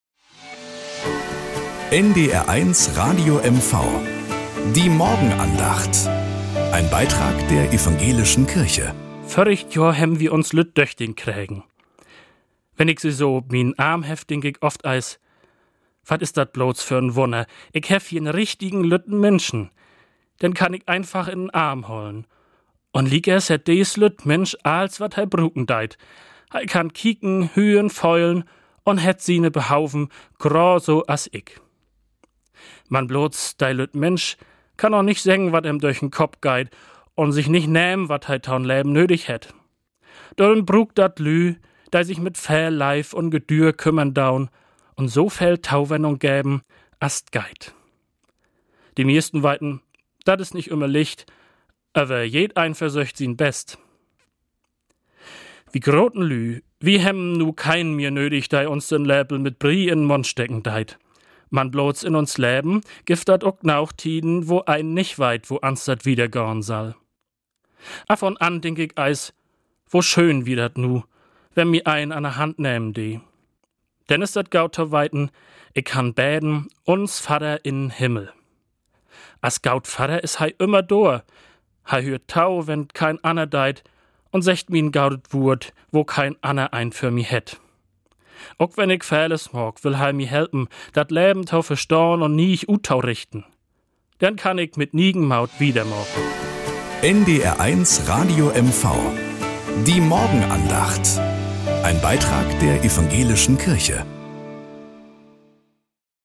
1 Morgenandacht auf NDR 1 Radio MV 1:46